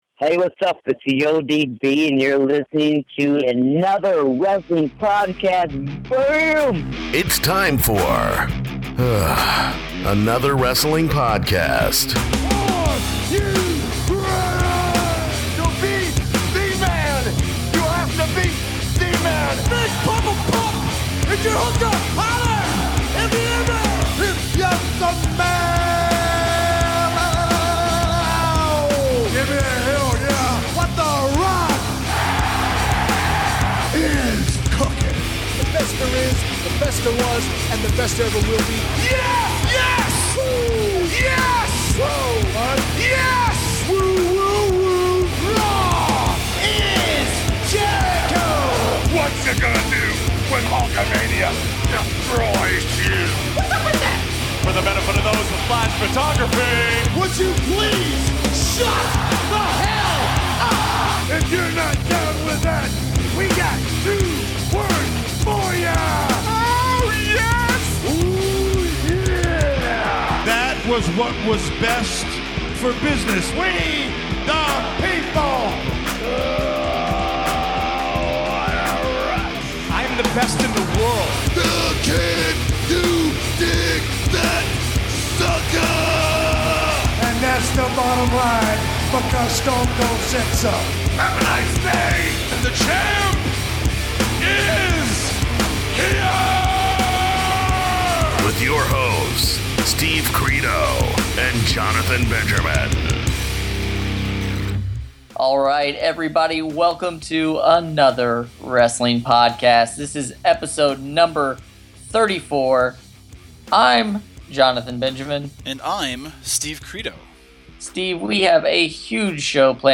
They talk about some of the greatest and most memorable weddings to ever take place in pro wrestling! They are also joined by the always outspoken former TNA knockout and current ROH superstar ODB Jessie Kresa!